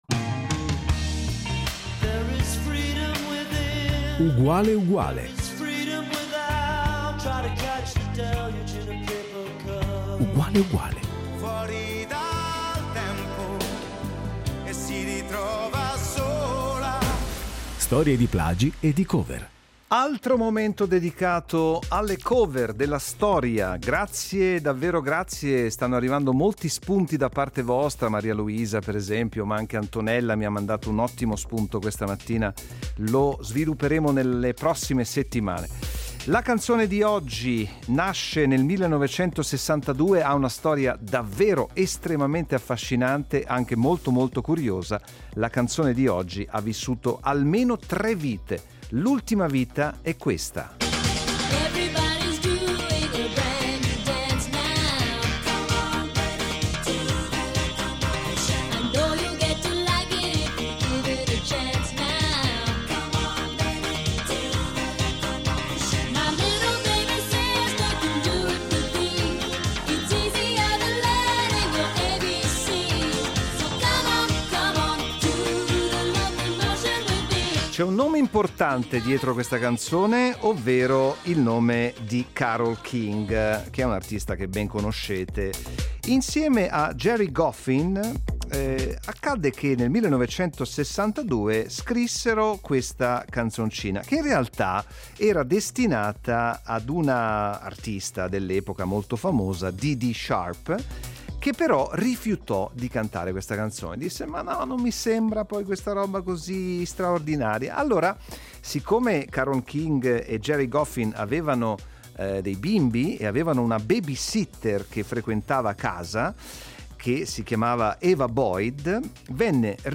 In questi sei decenni sono nate moltissime altre cover: nell’audio allegato potete sentire le voci di Sylvie Vartan, di Tina Turner e anche di Claudia Mori.